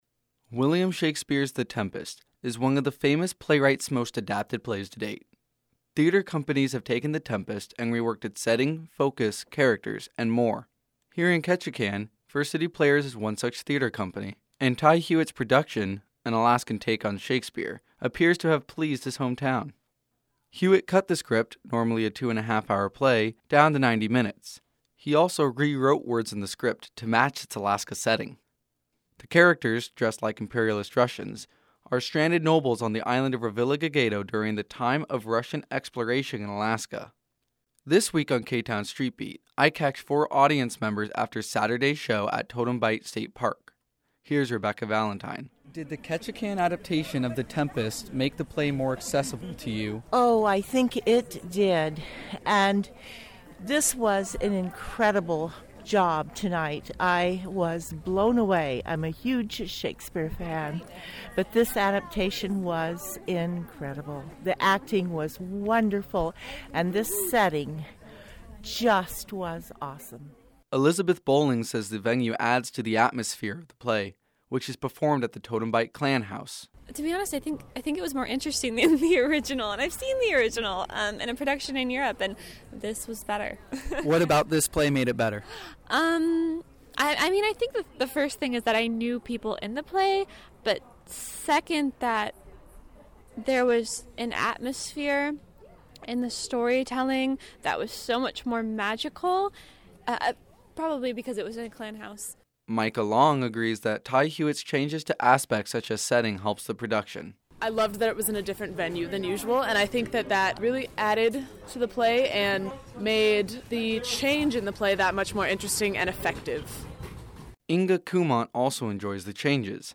This week on K-Town Street Beat, I caught four audience members after Saturday’s show at Totem Bight State Park. This week’s Street Beat question: “Did the Ketchikan adaptation of The Tempest make the play more accessible to you?”